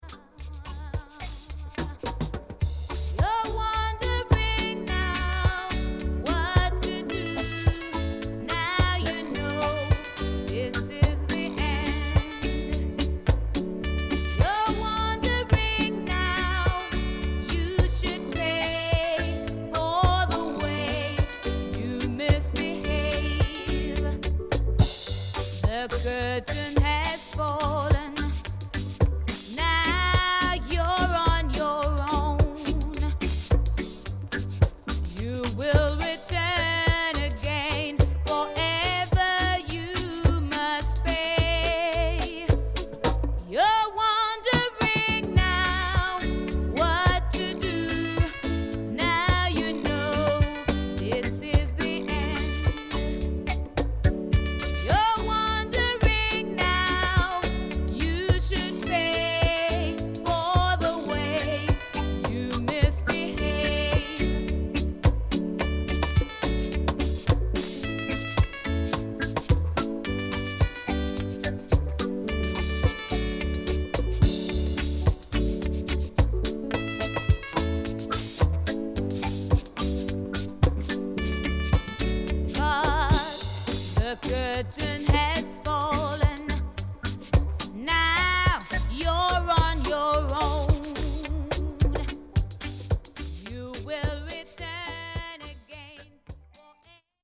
フィメール・ボーカリスト